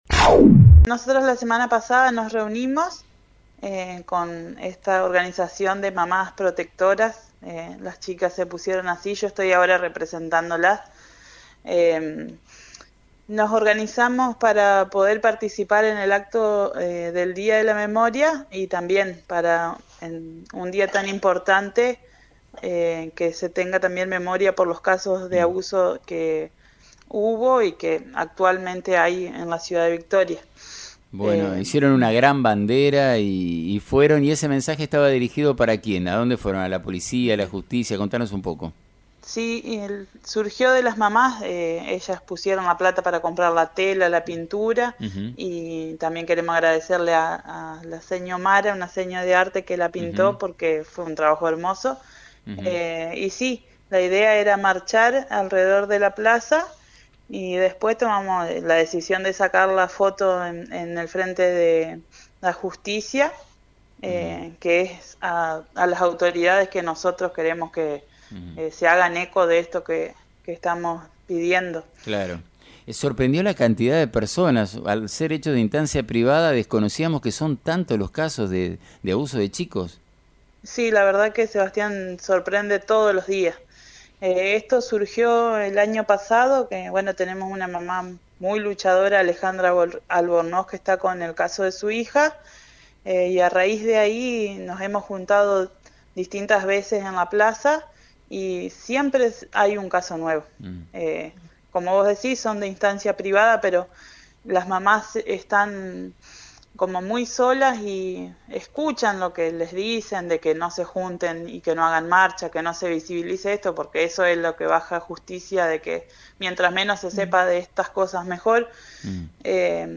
Una de sus integrantes dialogó con FM 90.3 y comentó el funcionamiento de esta agrupación, abocada a acompañar a las familias que pasan por situaciones de abuso infantil.